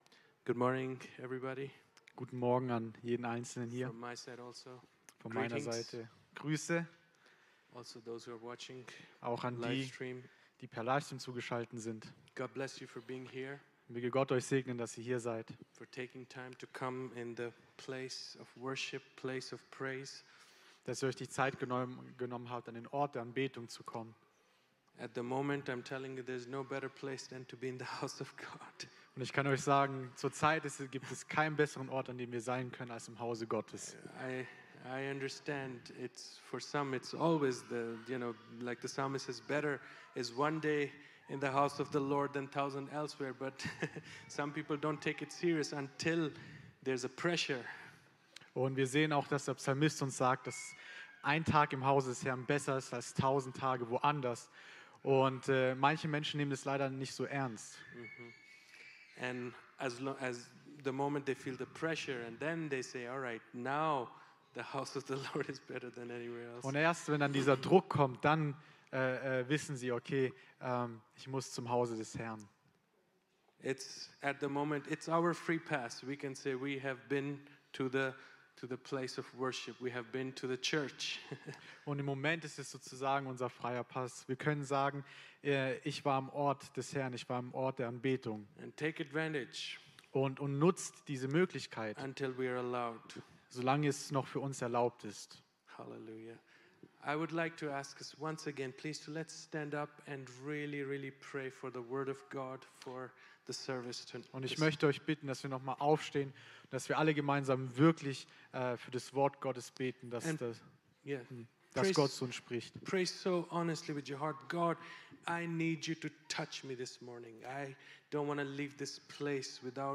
Predigt
im Christlichen Zentrum Villingen-Schwenningen. - Sprache: Englisch mit deutscher Übersetzung